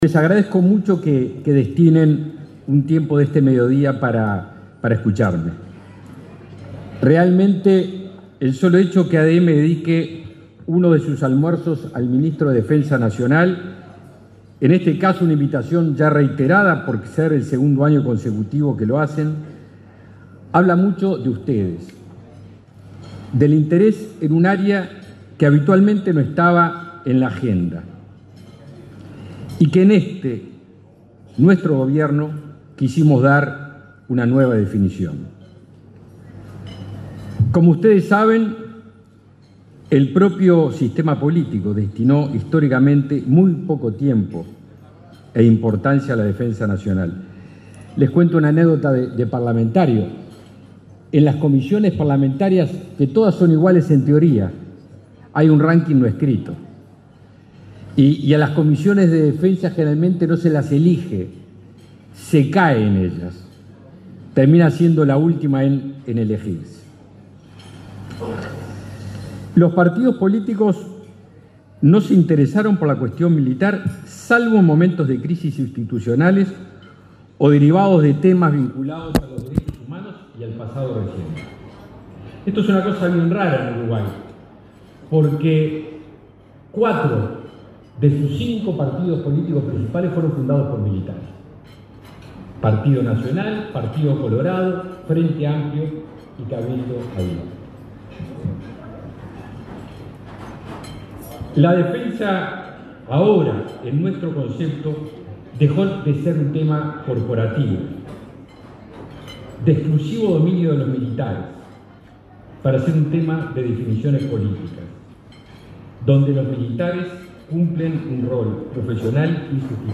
Disertación del ministro de Defensa Nacional, Javier García
Disertación del ministro de Defensa Nacional, Javier García 19/10/2023 Compartir Facebook X Copiar enlace WhatsApp LinkedIn El ministro de Defensa Nacional, Javier García, disertó, este jueves 19 en Montevideo, en un almuerzo de trabajo organizado por la Asociación de Dirigentes de Marketing.